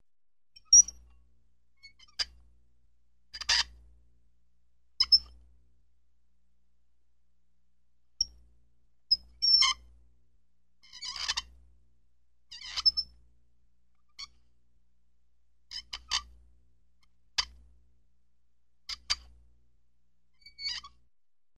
Звуки автосервиса
Звук затягивания гайки и скрип